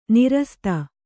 ♪ nirasta